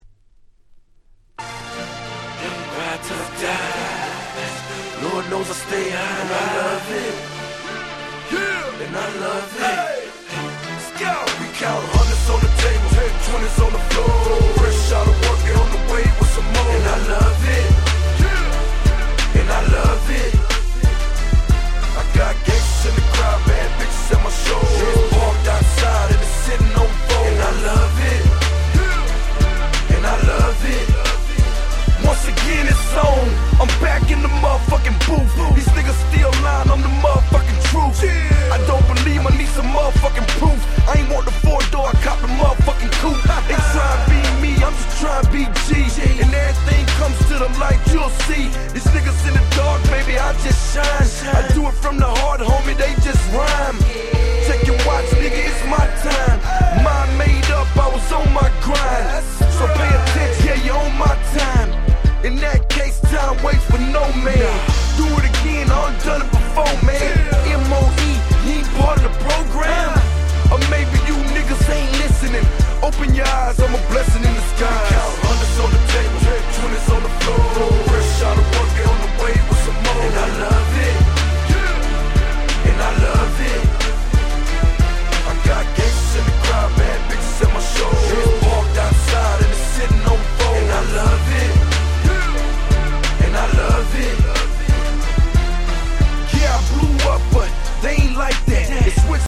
06' Super Hit Southern Hip Hop !!
まんま当時のAtlantaサウンドなハーコーシット！！